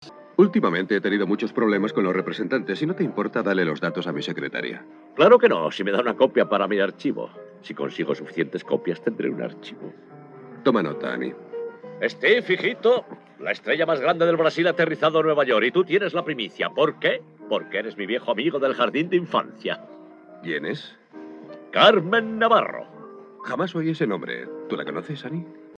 El audio del doblaje en castellano se presenta también en Dolby Digital 2.0 monoaural, a 384 Kbps. Su calidad es aceptable, con poco ruido de fondo y un volumen correcto, aunque la dinámica resulta ser mucho más limitada para la música que la de la pista en inglés, pero aún así puede considerarse como un sonido acorde con lo que suele encontrarse en películas de la época.
Según parece, las voces corresponderían a un redoblaje televisivo, con Carlos Revilla en el papel de Groucho.
muestra de audio del doblaje al castellano.